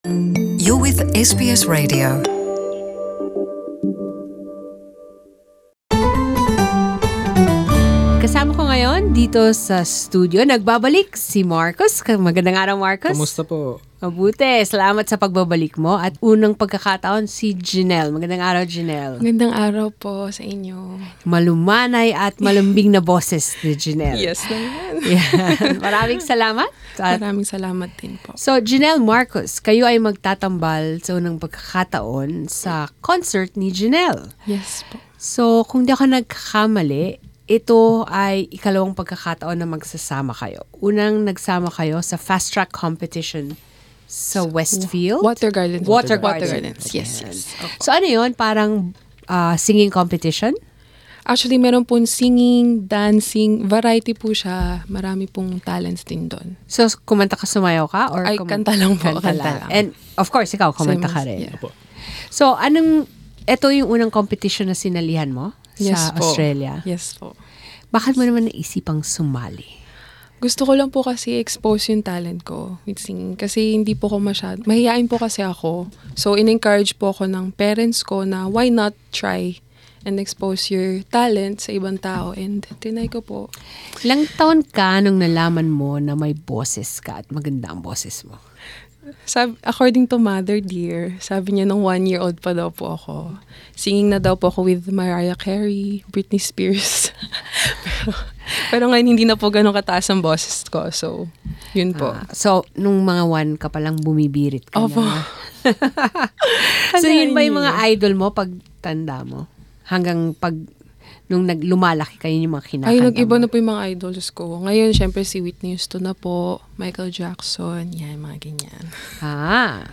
This Saturday, they perform some of their favourite songs .